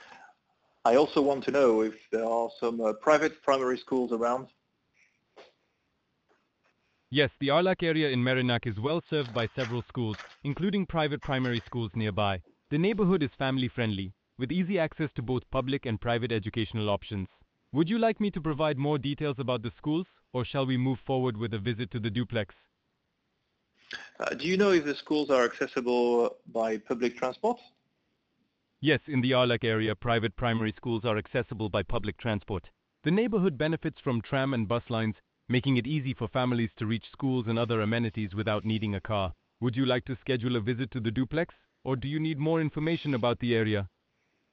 Listen to our AI in action
Discover our real‑estate‑specialist AI agent in these real‑world cases